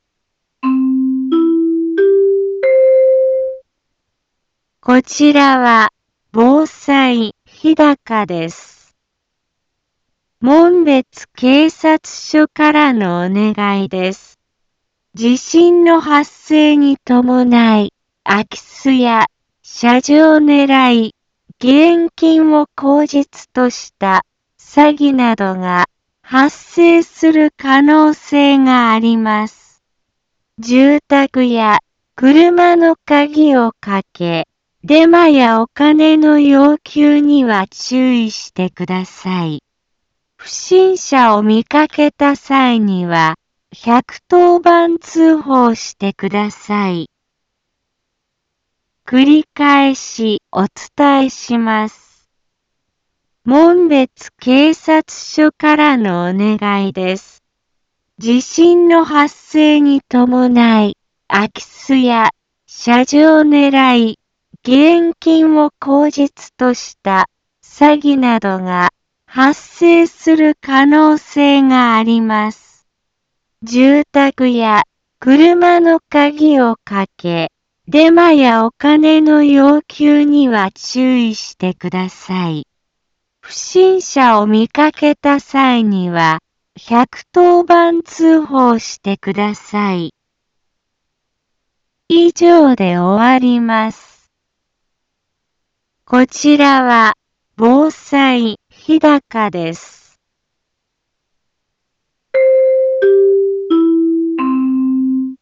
Back Home 一般放送情報 音声放送 再生 一般放送情報 登録日時：2018-09-13 17:03:47 タイトル：詐欺のご注意 インフォメーション：門別警察署からのお願いです。 地震の発生に伴い、空き巣や、しゃじょうねらい、義援金を口実とした、詐欺などが、 発生する可能性があります。